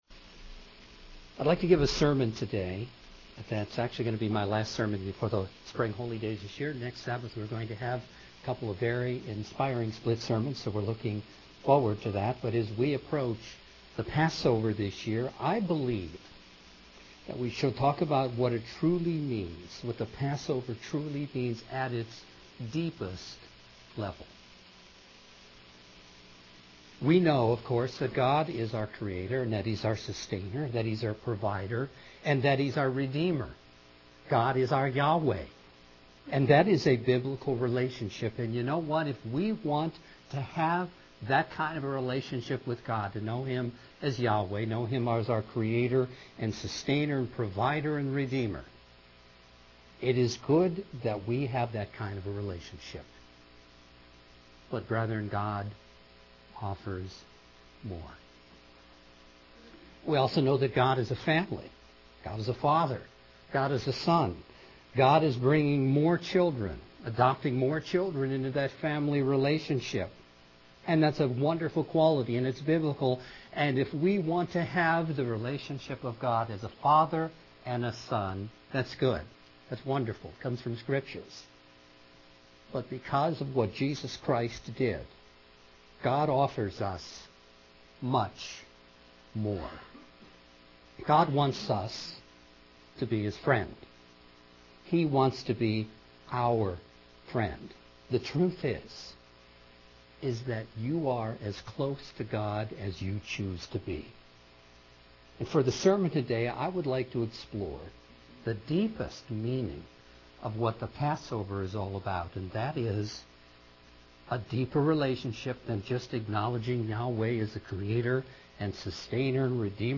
For the Sermon today let’s explore friendship with God and see ways we can enhance that friendship with our Father, and with the Son.